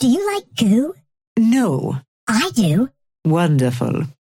Lady Geist and Viscous conversation 2